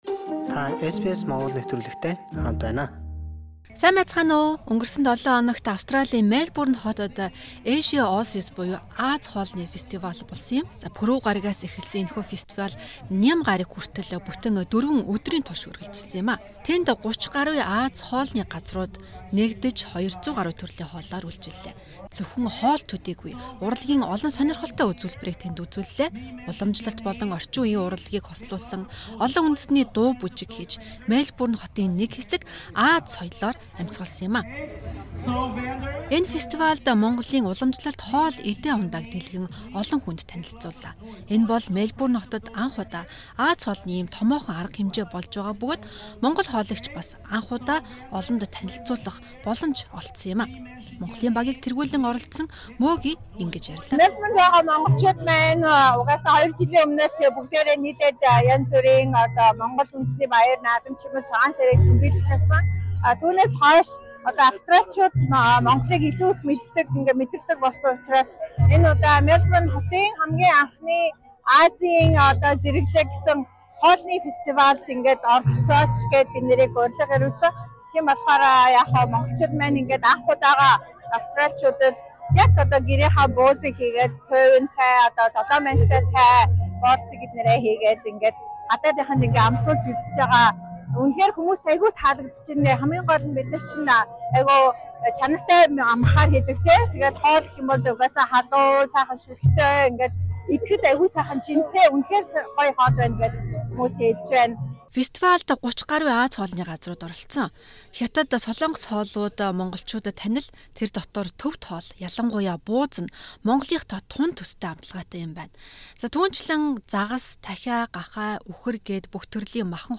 Монгол хоолыг анх удаа Мелбурнчуудад танилцууллаа |Ази хоолны фестивалиас сурвалжлав